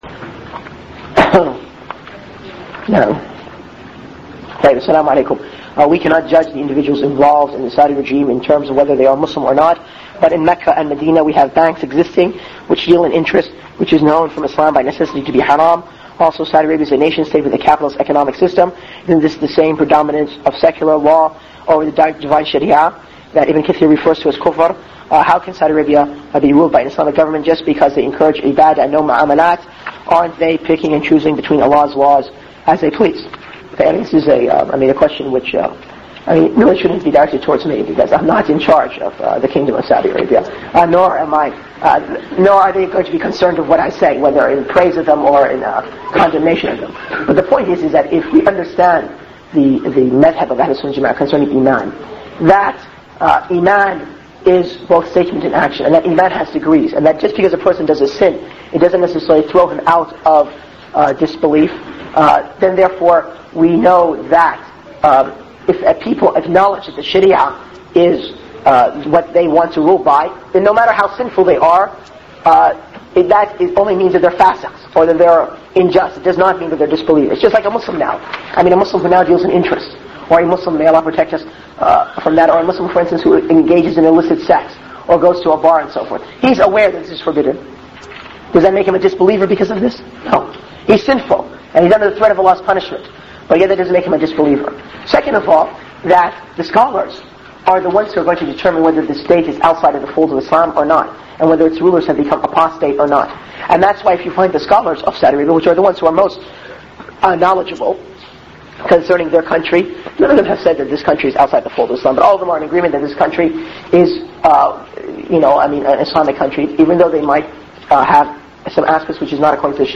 In this audio recording from prior to the mid 90s, Ali al-Timimi is asked regarding Shaykh Abdul-Azeez bin Baaz and the Gulf War, and he clarifies a presumption made in the question(s) posed.